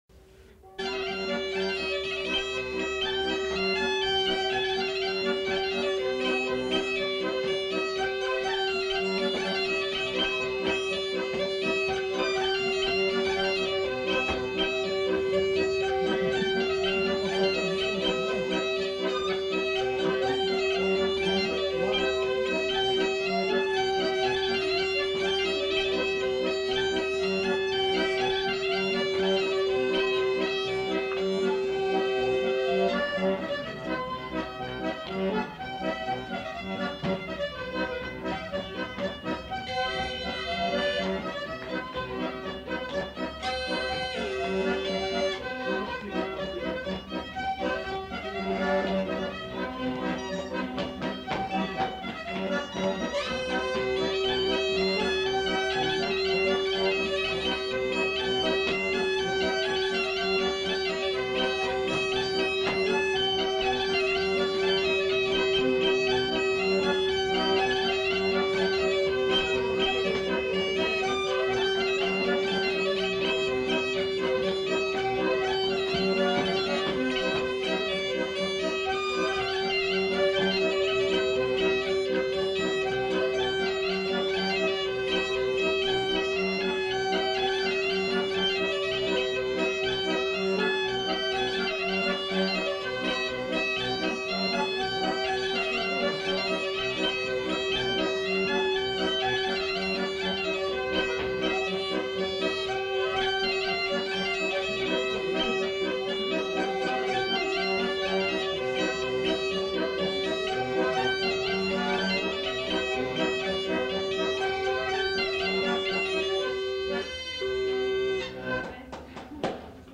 Perlinpinpin fòlc (ensemble instrumental)
Aire culturelle : Agenais
Lieu : Foulayronnes
Genre : morceau instrumental
Instrument de musique : accordéon diatonique ; boha ; violon
Danse : rondeau